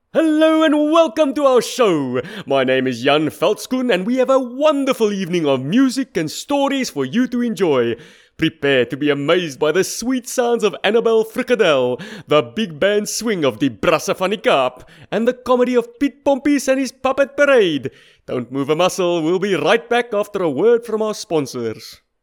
South African Accents
Brisbane (Home Studio)
Male, 30s, native of Sth Africa